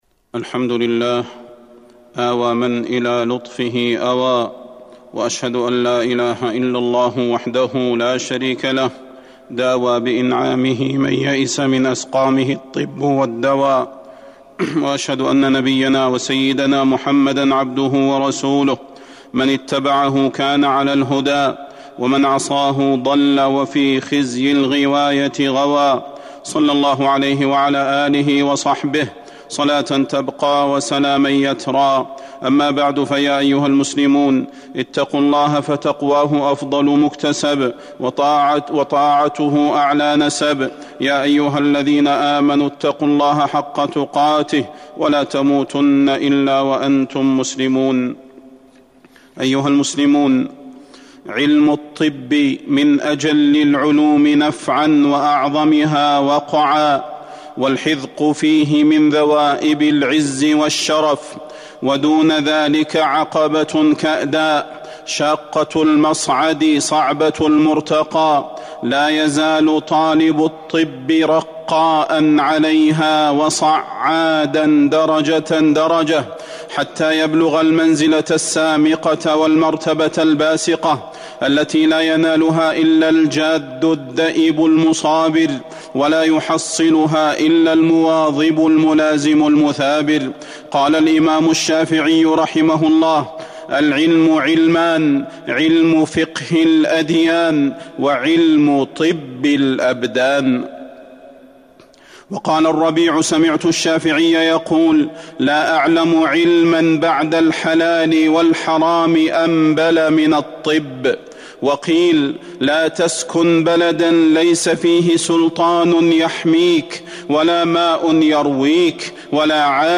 تاريخ النشر ١ صفر ١٤٤٢ هـ المكان: المسجد النبوي الشيخ: فضيلة الشيخ د. صلاح بن محمد البدير فضيلة الشيخ د. صلاح بن محمد البدير فضل الطب والأطباء